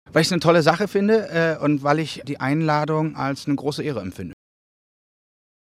DJ Paul van Dyk empfindet die Einladung zur Radiopreisgala als große Ehre. (Interview